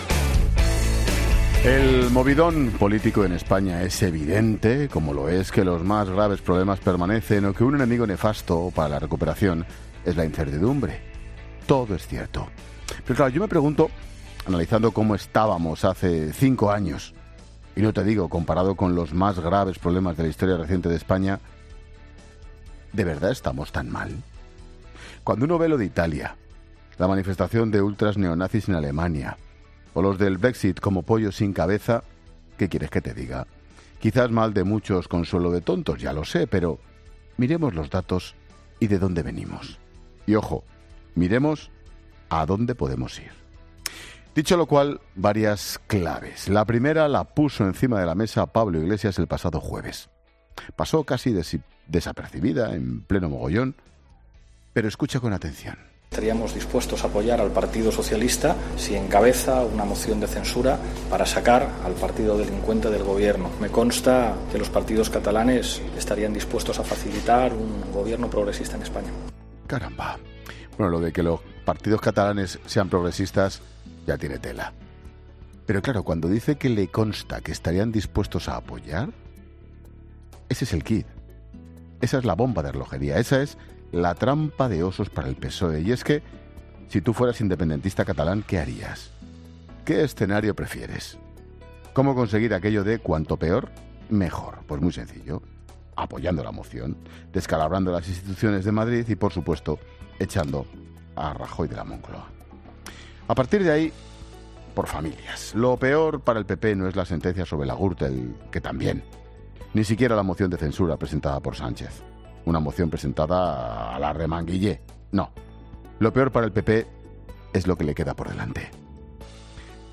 Monólogo de Expósito
El comentario de Ángel Expósito sobre la moción de censura del PSOE a Rajoy.